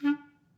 Clarinet
DCClar_stac_D3_v2_rr2_sum.wav